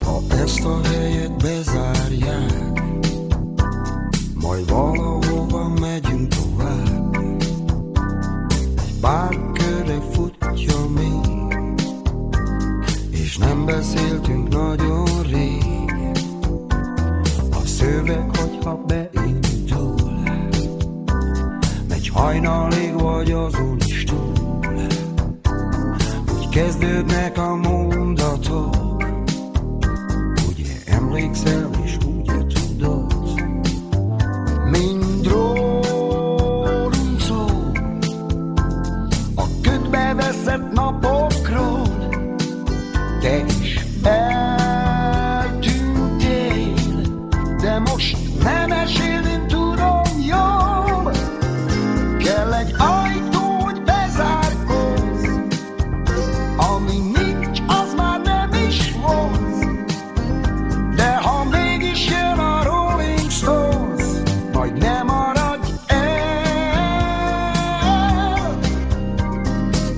Lattmann Béla: Bass